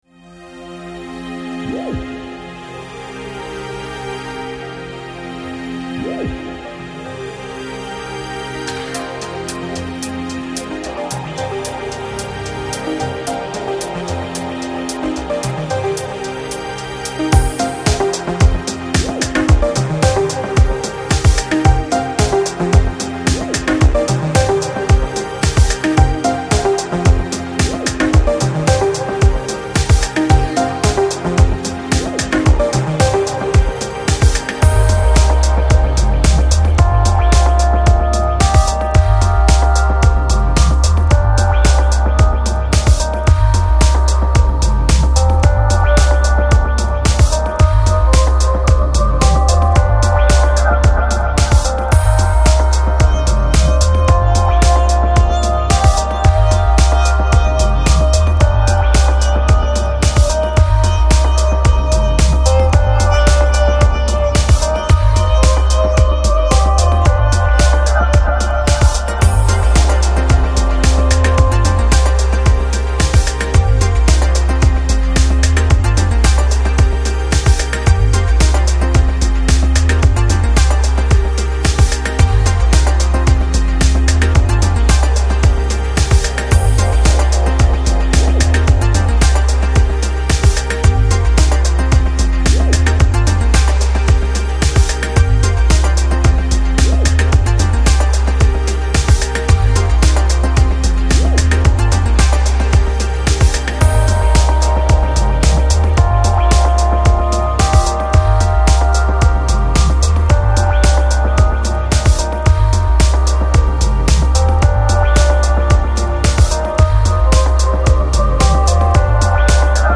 Жанр: Lounge, Chill Out, Jazz